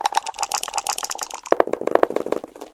dice.ogg